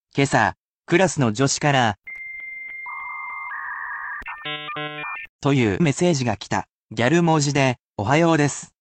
Each sentence is then read to you as many times as you wish, but it will be at full speed, so it is more useful to be used to pick the word out of the sentence rather than repeating.